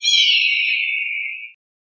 Descarga de Sonidos mp3 Gratis: peterodactilo.
pterodactyl-effects.mp3